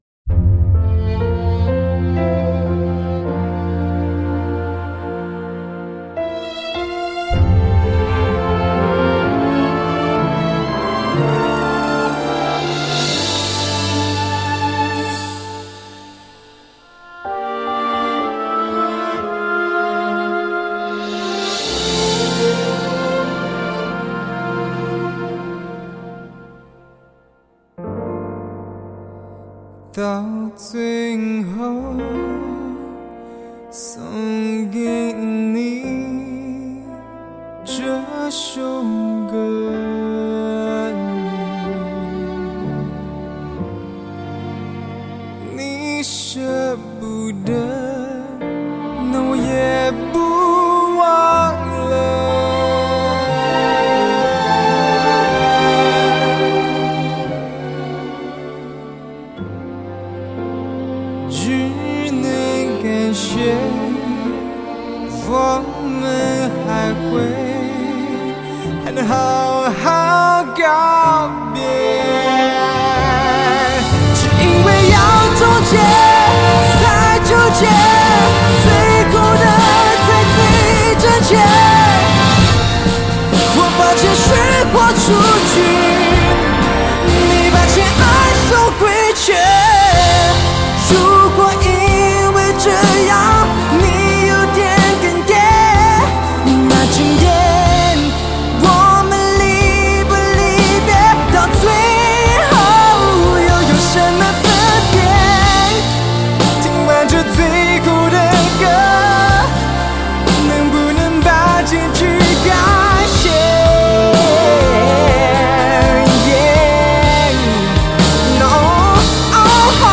如此纯粹的交响乐，有着迪斯尼般的华丽磅礡情境，让人听完带着隽永，回味无穷！